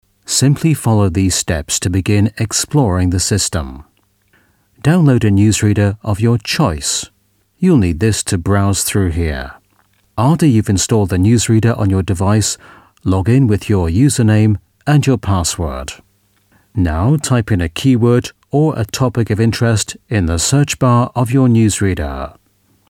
Native Speaker
Englisch (UK)
Explainer Videos